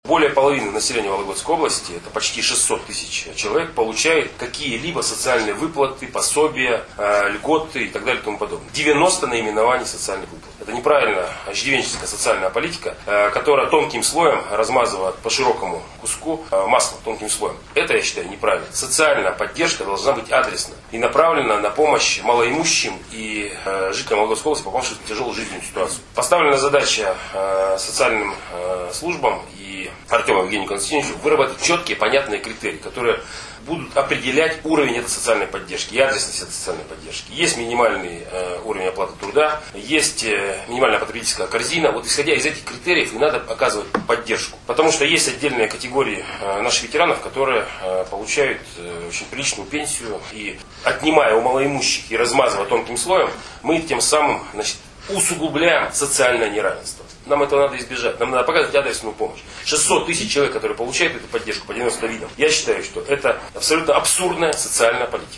Олег Кувшинников рассказывает о мерах социальной поддержки в Вологодской области